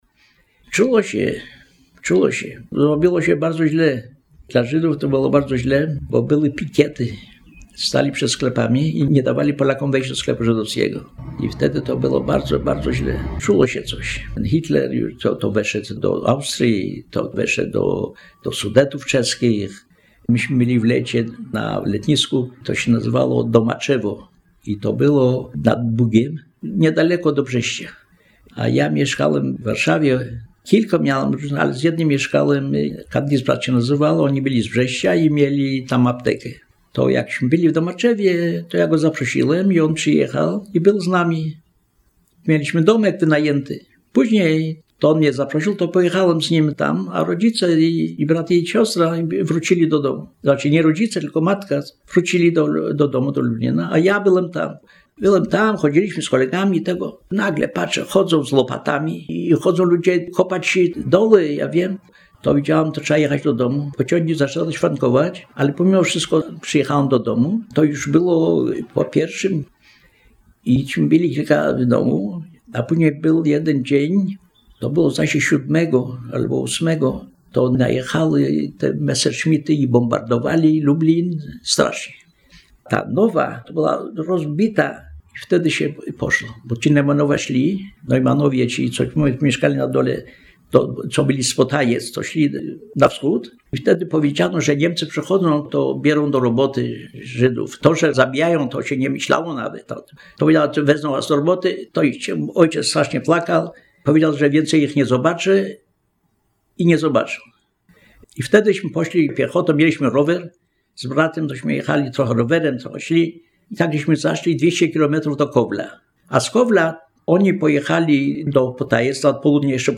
fragment relacji świadka historii
Relacja mówiona zarejestrowana w ramach Programu Historia Mówiona realizowanego w Ośrodku